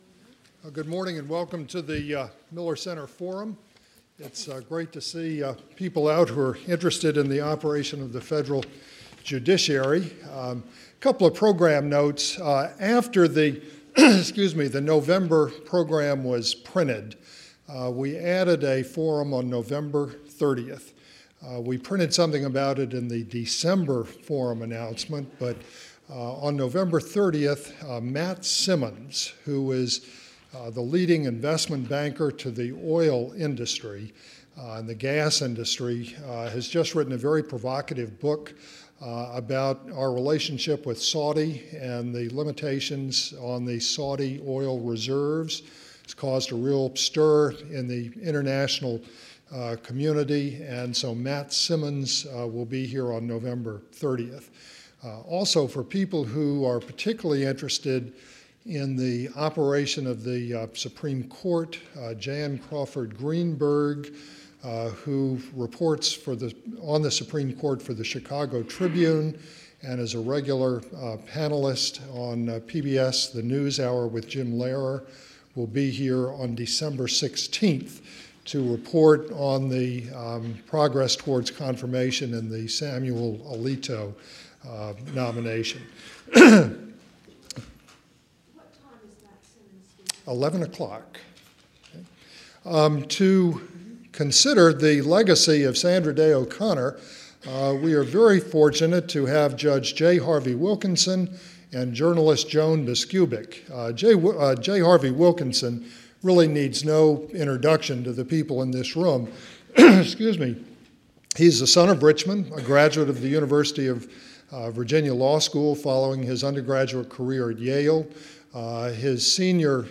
USA Today reporter and Washington Week commentator Joan Biskupic, author of Sandra Day O'Connor: How the First Woman on the Supreme Court Became Its Most Influential Justice, is joined by Fourth Circuit Court of Appeals Judge J. Harvie Wilkinson in a discussion evaluating O'Connor's legacy.